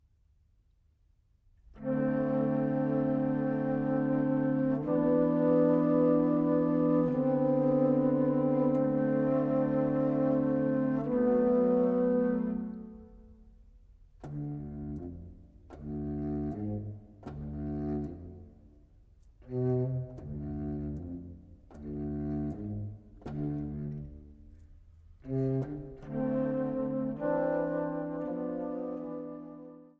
Piccoloflöte
Altflöte
Bassflöte
Kontrabassflöte